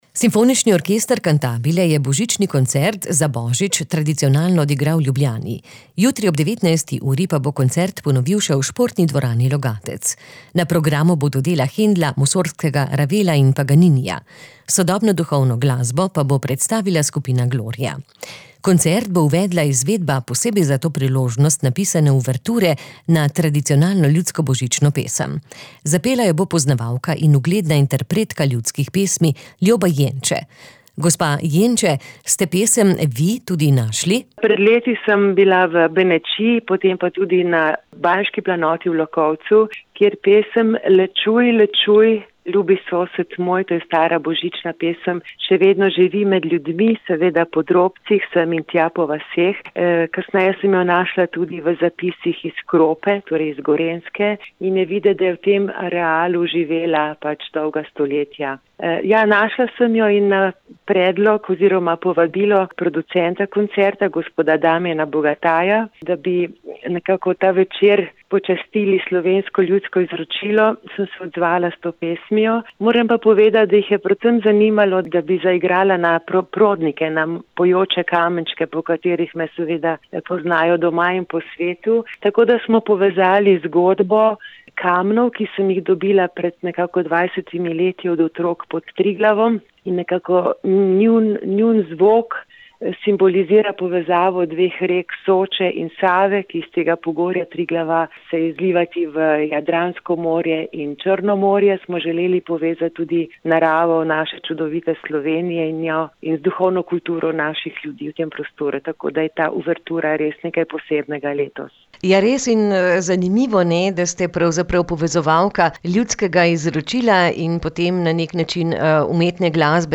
Ge za pesem Le čuj, le čuj, ljubi sosed moj. Stara božična pesem, ki jo je prvič slišala v Benečiji in nato še na Banjški planoti, ter jo odkrila v zapisih iz Krope, še živi med ljudmi. Ob petju prastare pesmi se Ljoba Jenče spremlja na prodnika iz Soče in Save ter na ta način povezuje prostor in njegovo duhovno kulturo.
Foto: Simfonični orkester Cantabile